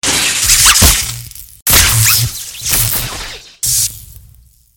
exp_television_sparks2.mp3